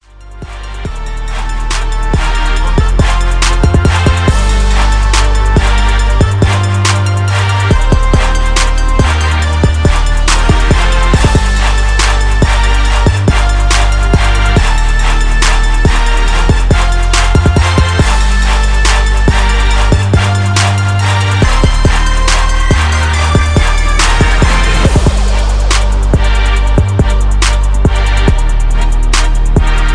a trap bass dropping instrumental